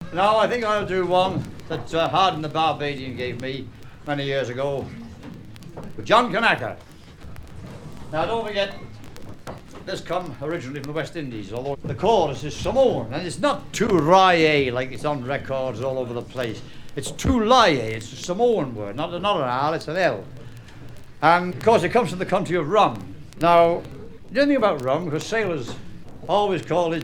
présentation de chansons maritimes
Catégorie Témoignage